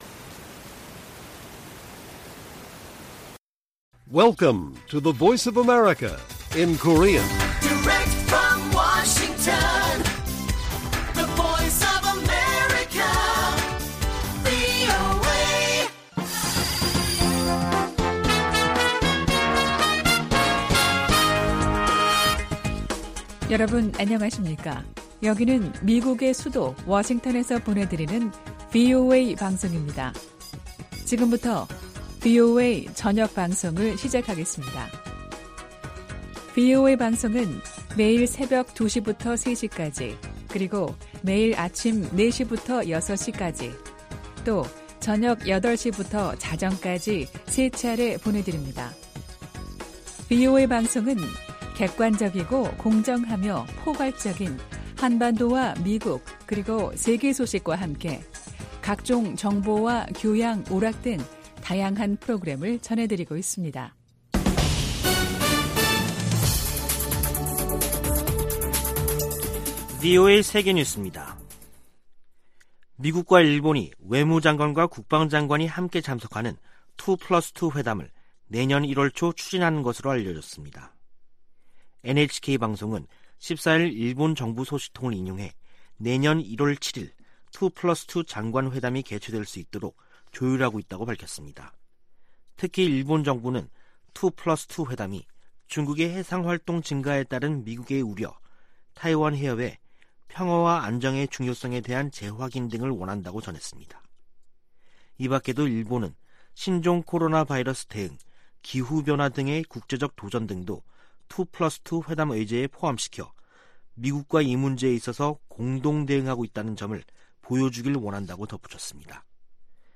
VOA 한국어 간판 뉴스 프로그램 '뉴스 투데이', 2021년 12월 15일 1부 방송입니다. 미국은 북한과 진지하고 지속적인 외교를 추구하고 있다고 토니 블링컨 국무장관이 밝혔습니다.